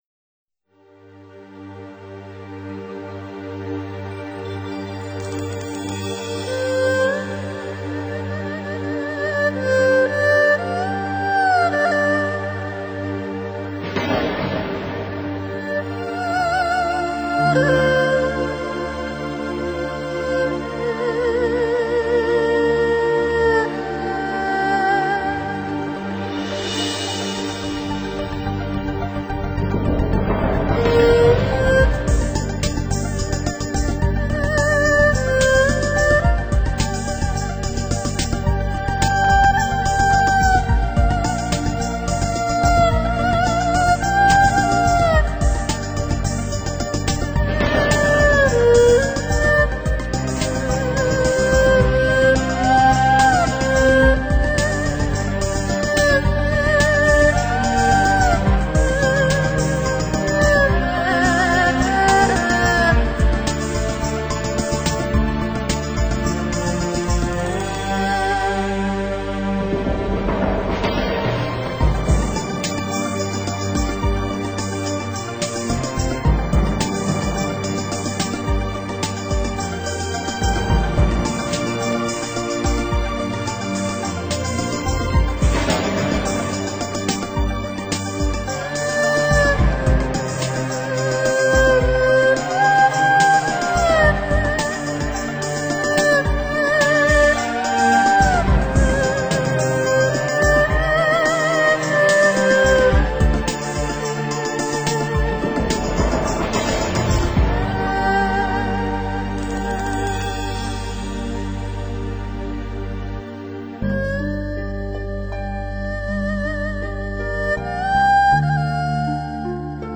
人琴合一的演奏境界，神乎其技；完美的意境詮釋，無懈可擊；穩而飽滿的快節拍，出神入化！